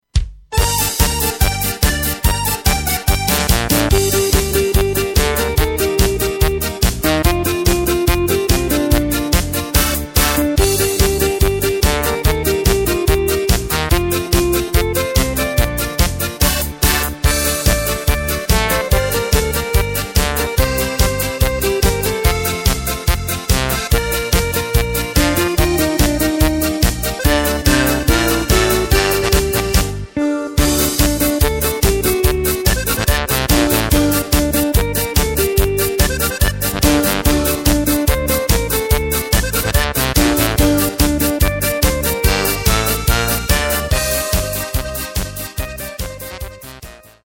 Takt:          4/4
Tempo:         144.00
Tonart:            Gm
Schlager-Polka (Cover) aus dem Jahr 2020!